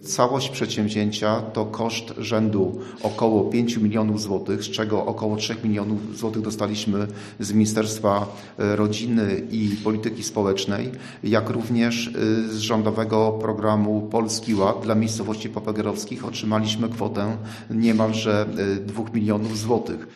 Wójt Wiśniewski dodał, że wsparcie finansowe pochodzi z różnych stron: